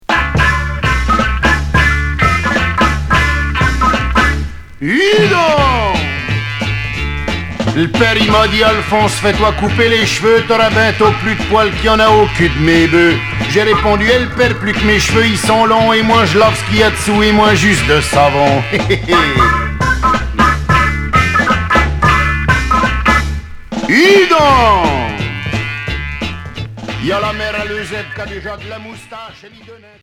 Chanteurs 60's